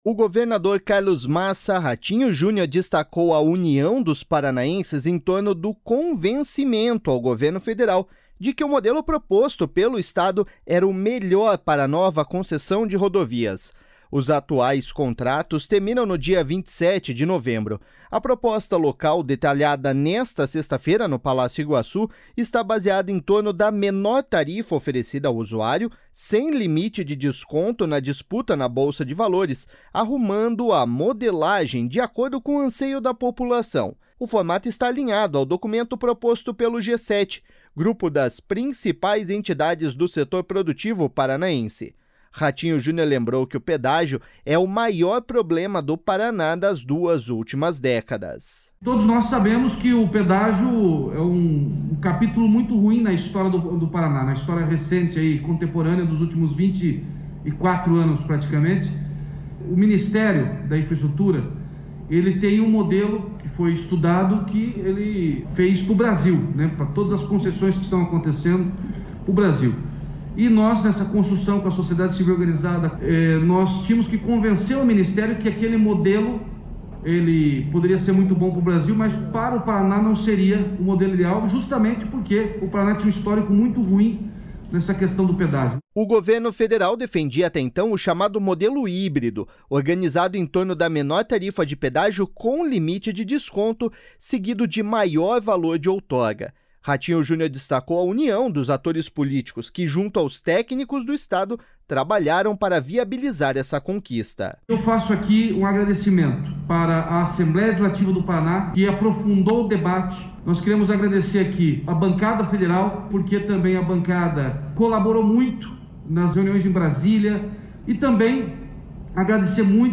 Ratinho Junior lembrou que o pedágio é o maior problema do Paraná das últimas duas décadas.// SONORA RATINHO JUNIOR.//
Para o presidente da Assembleia Legislativa, Ademar Traiano, a conquista é um divisor de águas para o Estado.// SONORA DEMAR TRAIANO.//
Coordenador da bancada do Estado em Brasília, o deputado federal Toninho Wandscheer também ressaltou o trabalho conjunto em torno desse objetivo.// SONORA TONINHO WANDSCHEER